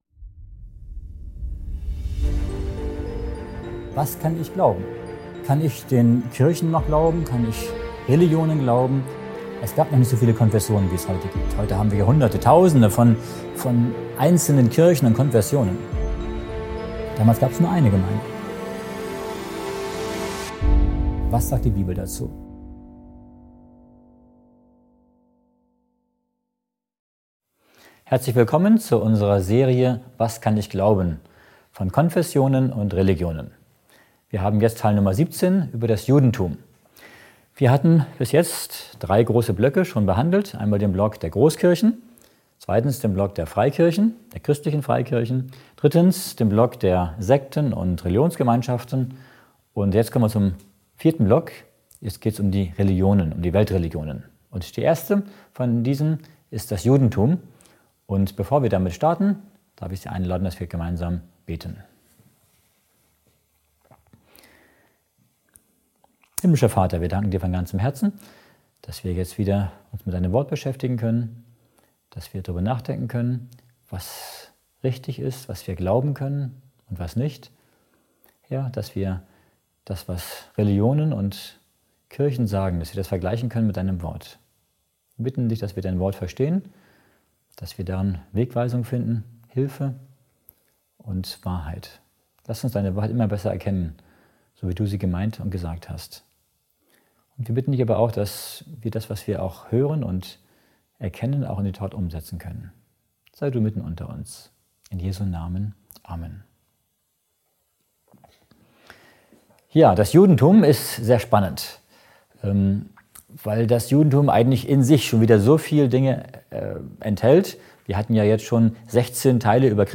Der Vortrag beleuchtet das Judentum als erste Weltreligion und zieht Vergleiche zur Bibel. Historische Aspekte, Lehren und Abweichungen werden thematisiert. Besonders betont wird die Bedeutung biblischer Prophezeiungen für den Messias Jesus.